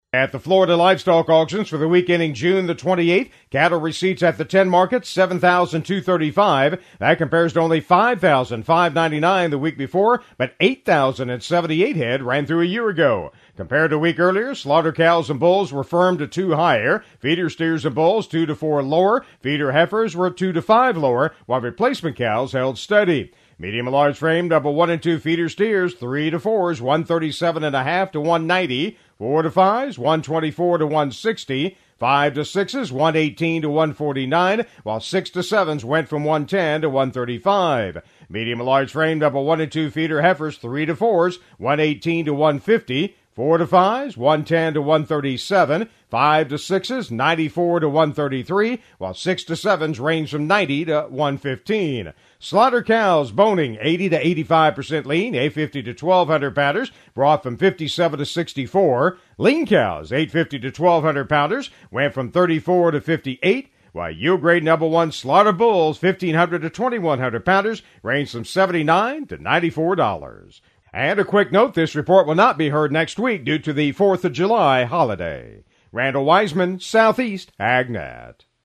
FL Livestock Market Report: